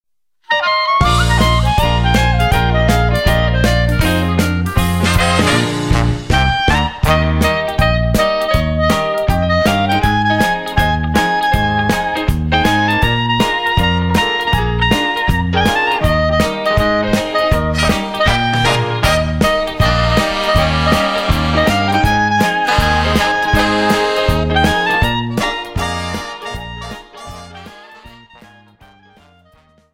JIVE